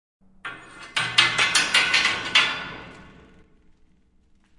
废弃的工厂 金属后世界末日的回声 " 刘海和混响 1
描述：记录在都柏林的一家废弃工厂。
Tag: 工业 工厂 金属 崩溃 噪声